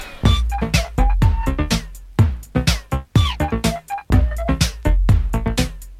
rap song
it's quite an addictive tune